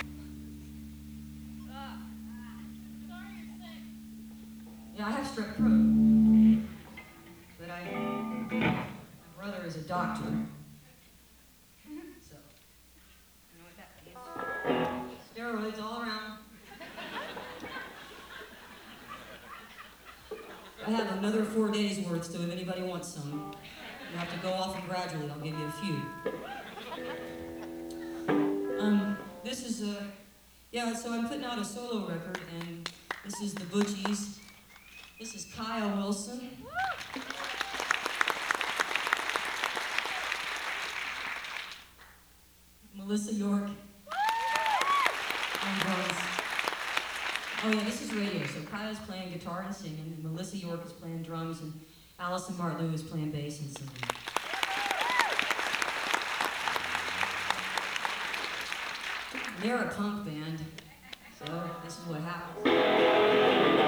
cultural center theatre - charleston, west virginia